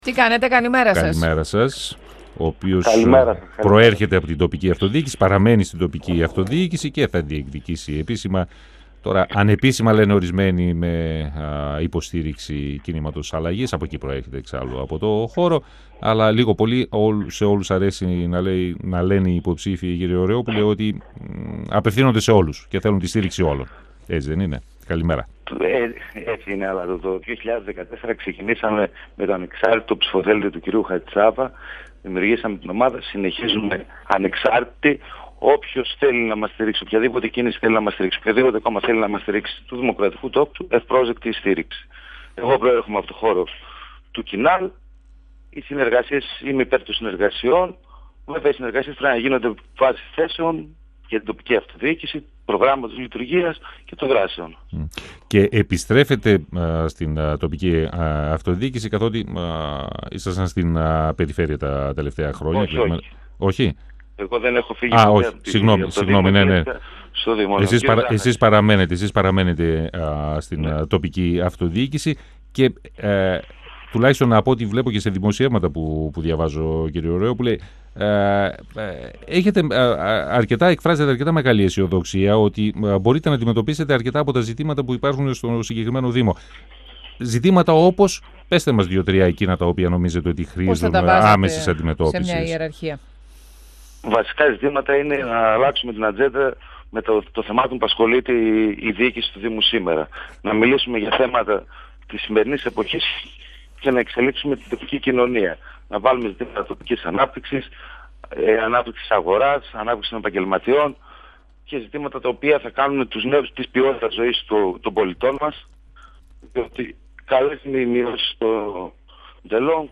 Ο δημοτικός σύμβουλος του δήμου Νεάπολης Συκεών , Λάζαρος Ωραιόπουλος, στον 102FM του Ρ.Σ.Μ. της ΕΡΤ3